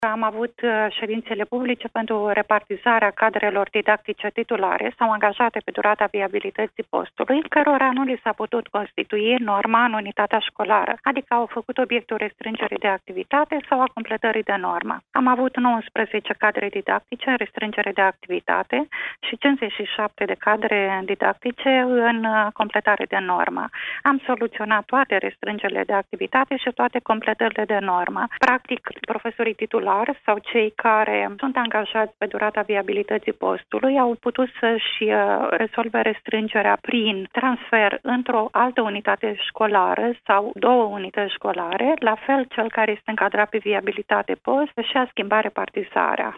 Într-o declarație pentru postul nostru de radio, șefa Inspectoratului Școlar Județean Iași, Rodica Leontieș, a declarat că toate situațiile deosebite ale profesorilor titulari au fost rezolvate favorabil.